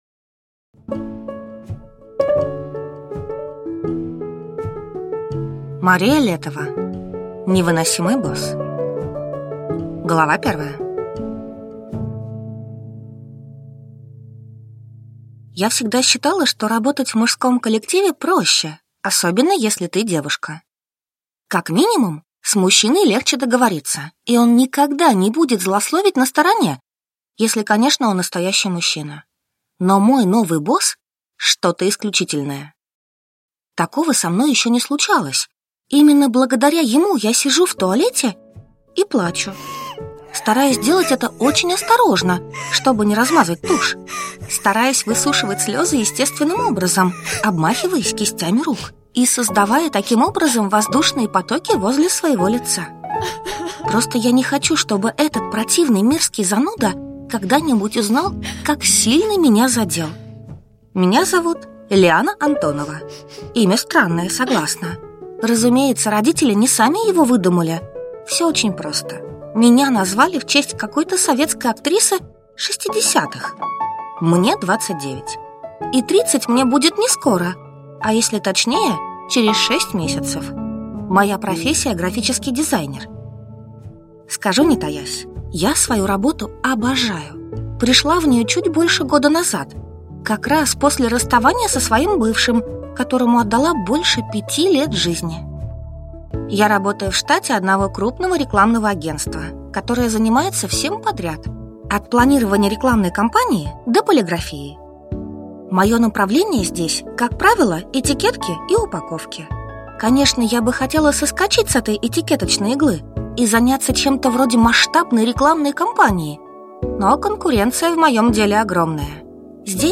Аудиокнига Невыносимый босс | Библиотека аудиокниг